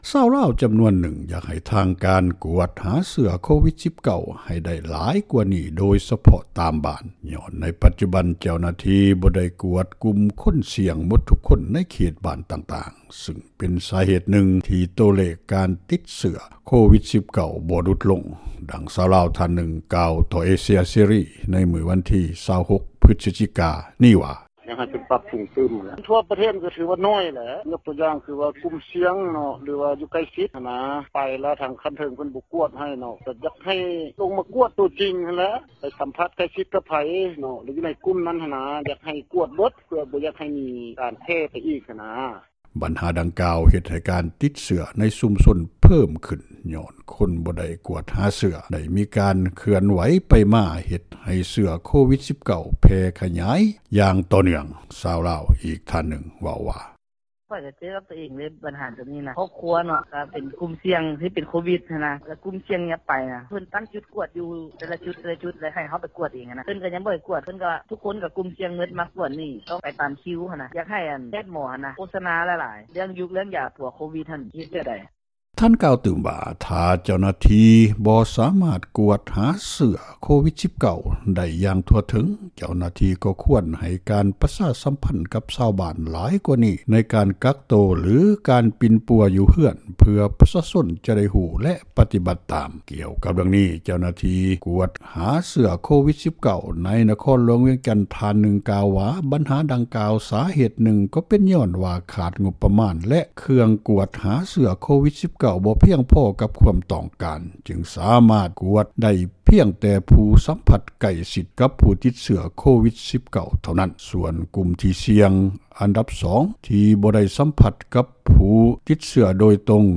ກວດຫາ ໂຄວິດ-19 ບໍ່ທົ່ວເຖິງ, ເພີ່ມຜູ້ຕິດເຊື້ອ — ຂ່າວລາວ ວິທຍຸເອເຊັຽເສຣີ ພາສາລາວ
ຊາວລາວຈຳນວນນຶ່ງ ຢາກໃຫ້ເຈົ້າໜ້າທີ່ກ່ຽວຂ້ອງ ລົງພື້ນທີ່ກວດຫາເຊື້ອໂຄວິດ-19 ຫຼາຍກວ່ານີ້  ໂດຍສເພາະ ຕາມບ້ານຍ້ອນວ່າ ໃນປັດຈຸບັນ ເຈົ້າໜ້າທີ່ບໍ່ໄດ້ກວດກຸ່ມທີ່ສ່ຽງ ໝົດທຸກຄົນໃນເຂດບ້ານຕ່າງໆ ຊຶ່ງເປັນສາເຫດນຶ່ງ ທີ່ໂຕເລກການຕິ້ດເຊື້ອໂຄວິດ-19 ບໍ່ຫລຸດລົງໃນລາວ ດັ່ງຊາວລາວ ທ່ານນຶ່ງກ່າວຕໍ່ເອເຊັຽເສຣີ ໃນວັນທີ 26 ພຶສຈິກາ ນີ້ວ່າ: